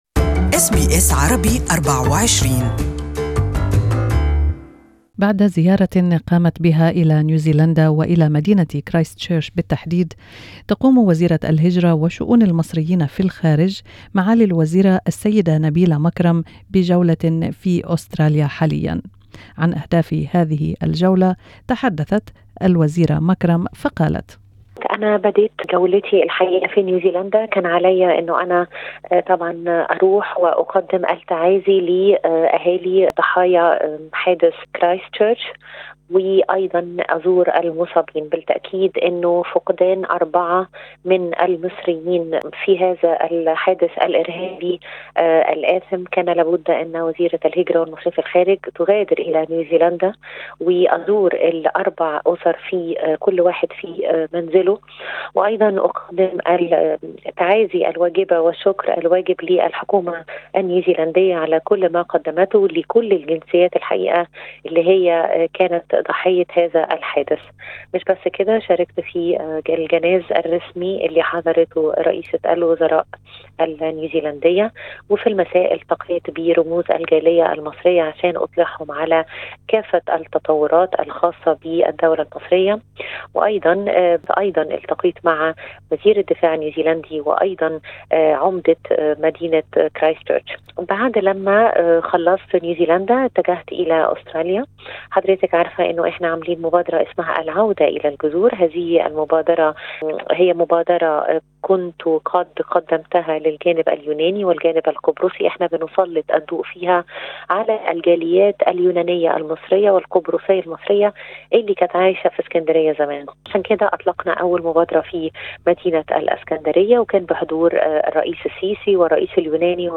She spoke to SBS Arabic24 about this visit and her visit to New Zealand.